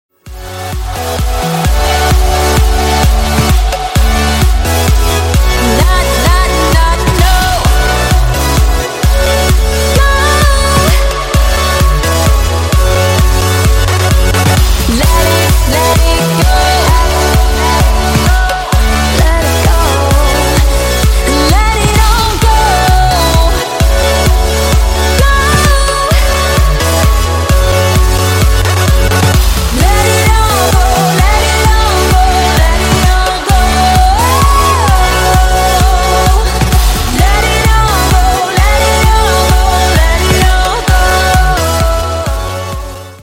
Клубные Рингтоны
Рингтоны Электроника